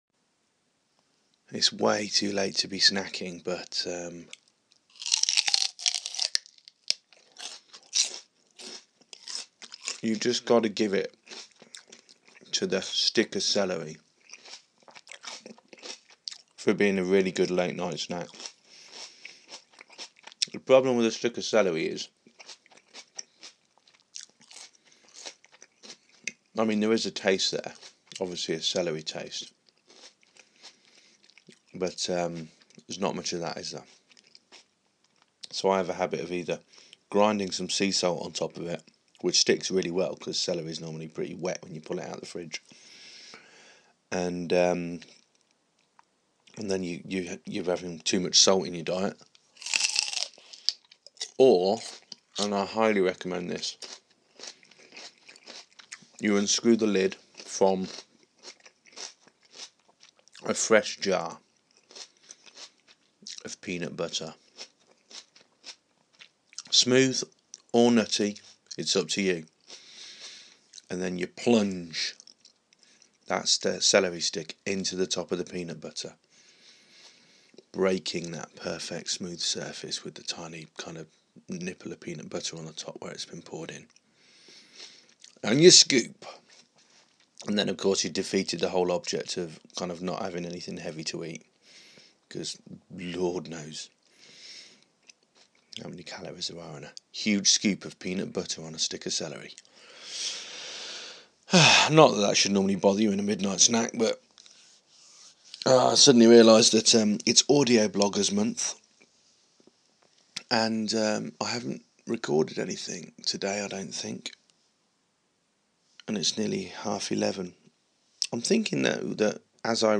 Munching celery, sampling Irish whiskey